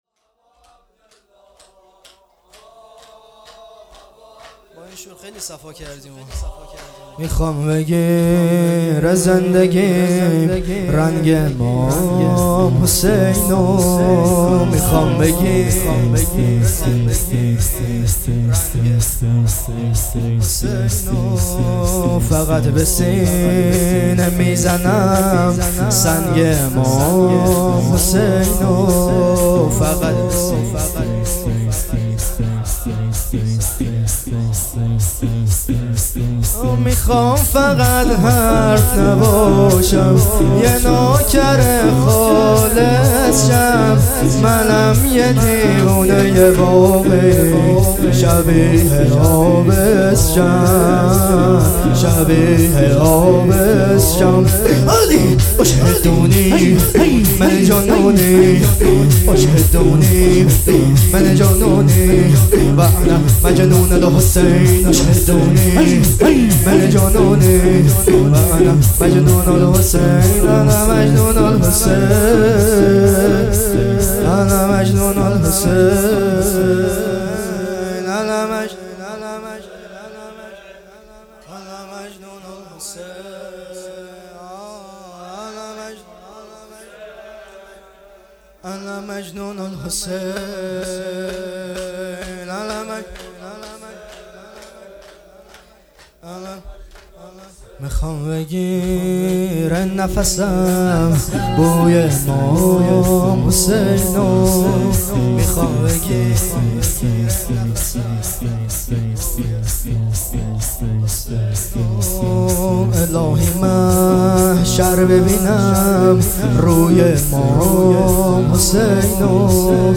مراسم هفتگی شهادت حضرت رباب (س)۱۴-۱۲-۹۹